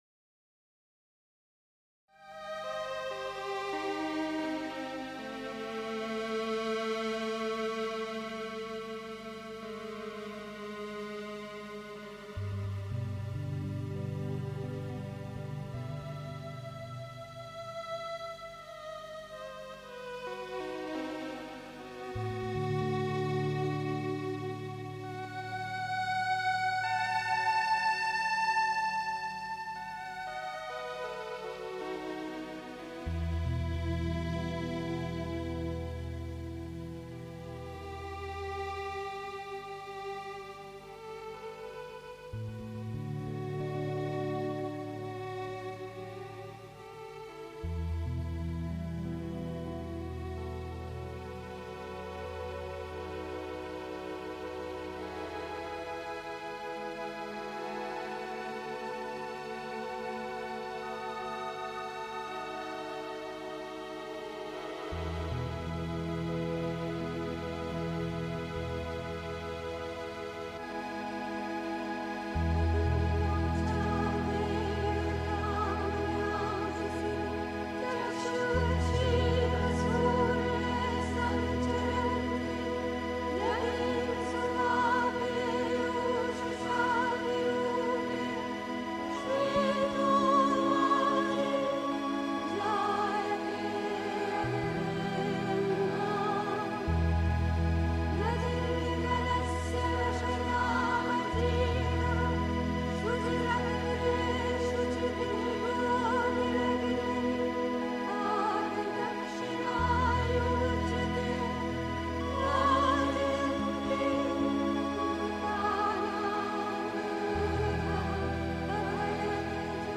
Beschreibung vor 1 Jahr 1. Einstimmung mit Sunils Musik aus dem Sri Aurobindo Ashram, Pondicherry. 2. Das Rufen der Kraft der Mutter (Sri Aurobindo, CWSA Vol. 32, p. 298) 3. Zwölf Minuten Stille.